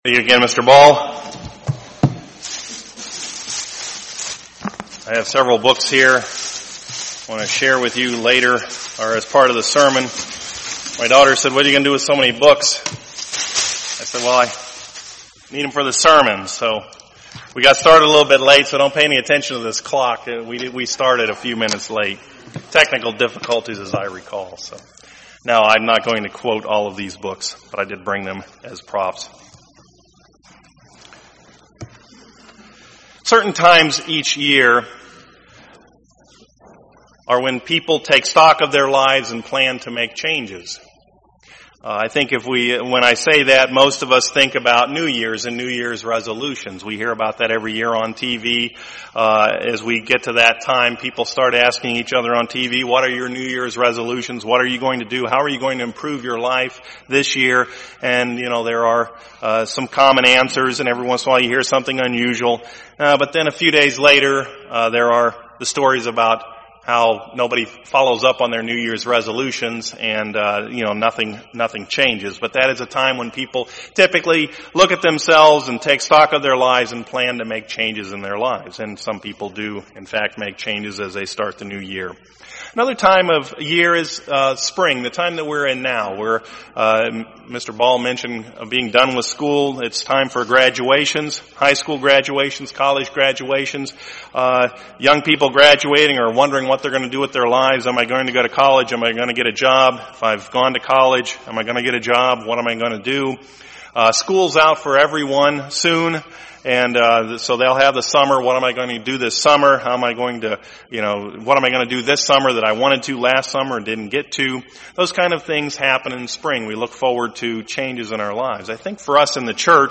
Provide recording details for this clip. Given in Pittsburgh, PA